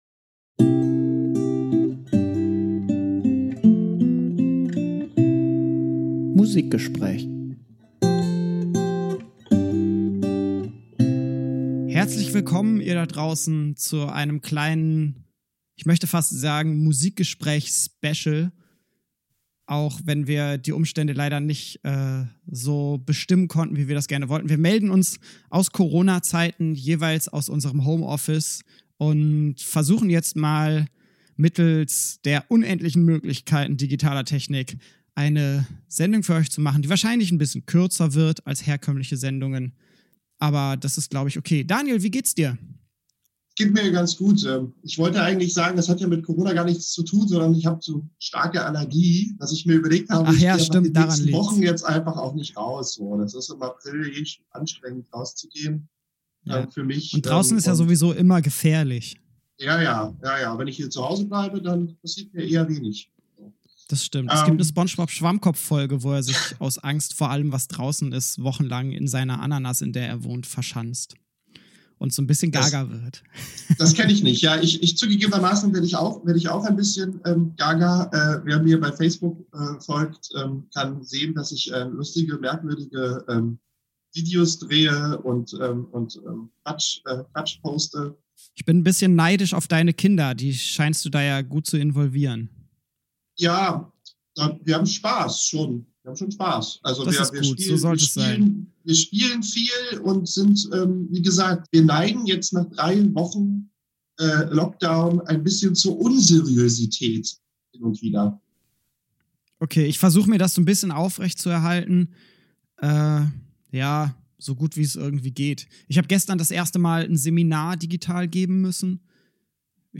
Leider hat die Technik uns etwas Probleme bereitet, Flow und Tonqualität haben darunter etwas gelitten. Dennoch viel Spaß mit dem Musikgespräch aus dem Home Office!